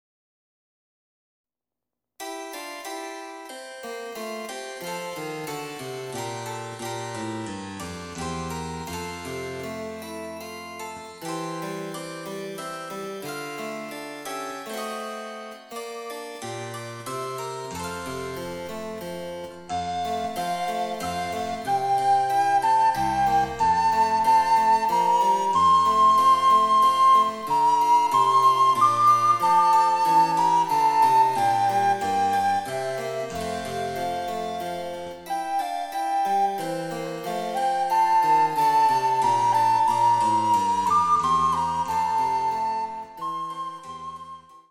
・チェンバロ伴奏
・リコーダー演奏例